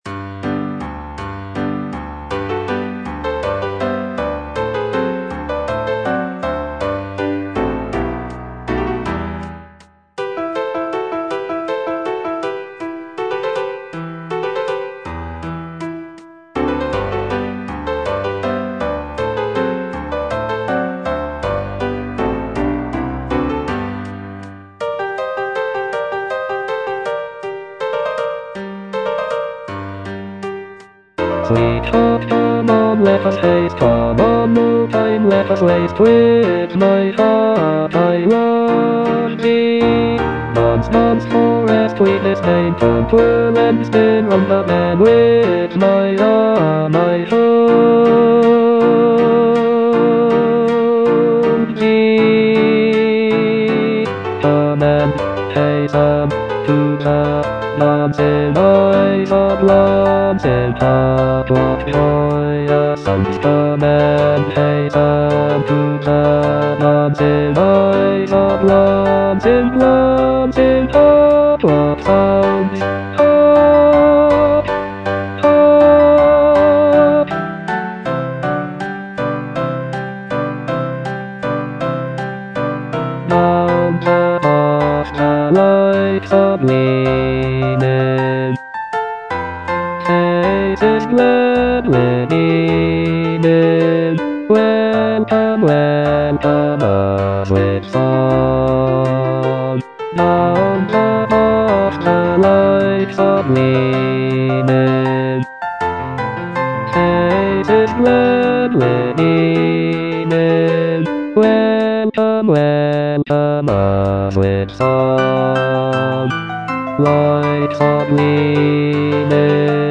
E. ELGAR - FROM THE BAVARIAN HIGHLANDS The dance (bass II) (Voice with metronome) Ads stop: auto-stop